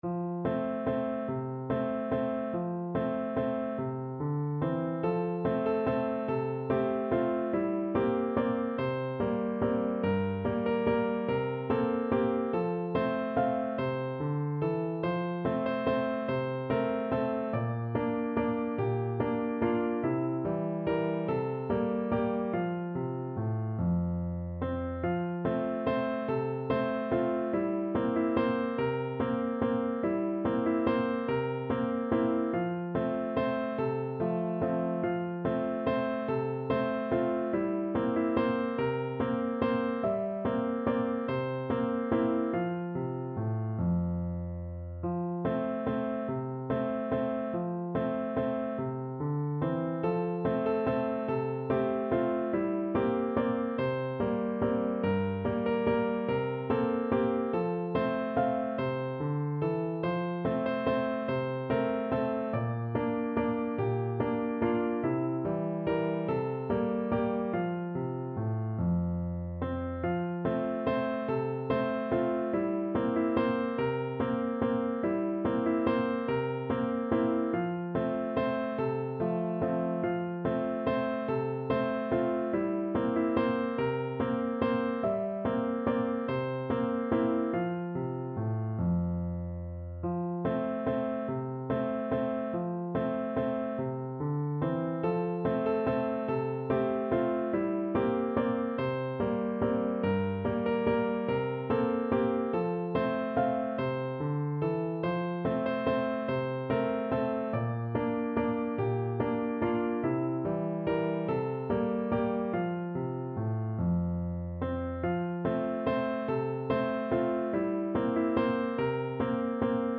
Piano version
solo piano
de_fruhlig_PNO.mp3